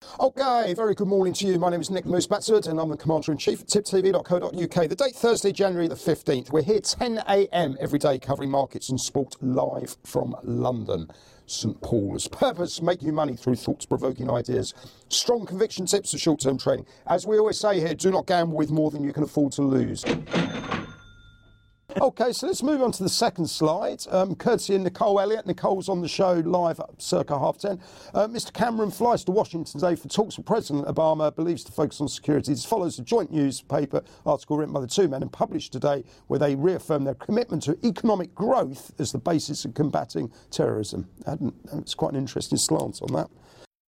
Live Market Round-Up & Soapbox thoughts.